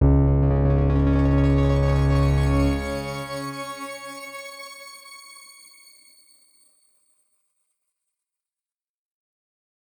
X_Grain-C#1-ff.wav